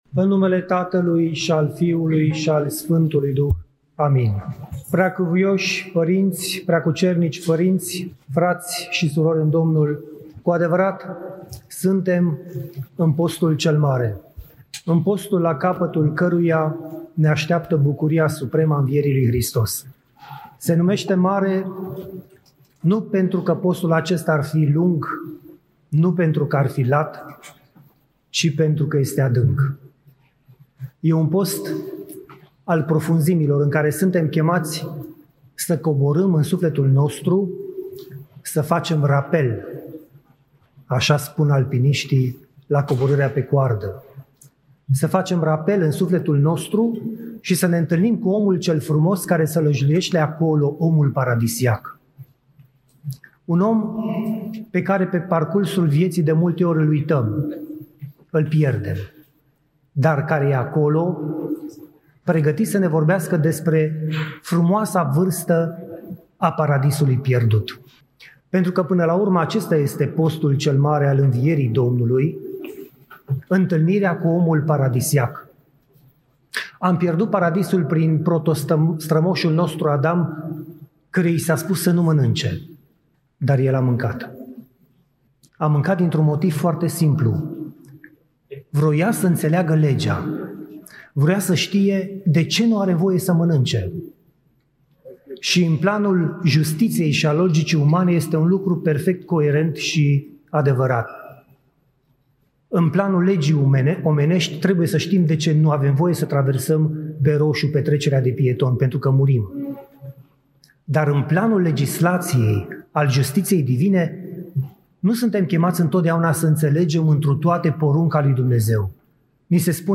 rostit în Catedrala Mitropolitană din Cluj-Napoca
Cuvinte de învățătură Să coborâm în adâncul sufletului după omul paradisiac